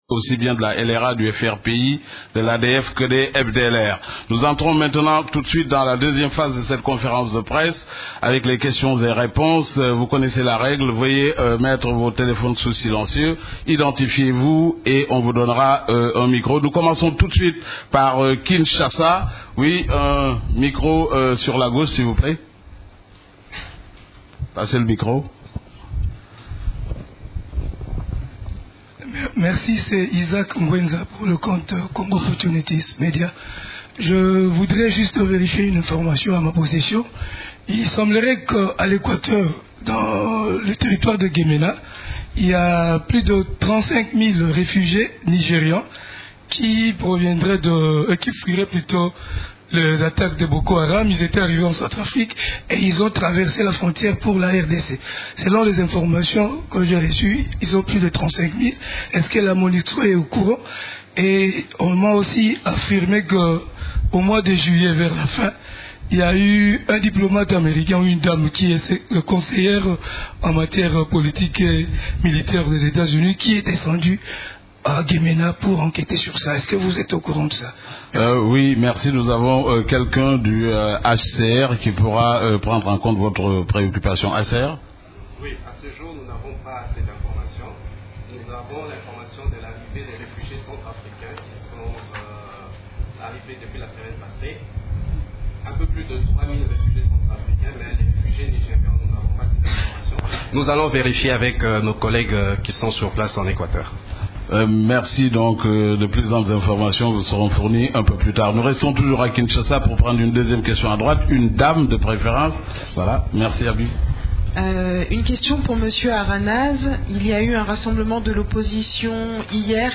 Conférence de presse du mercredi 16 septembre 2015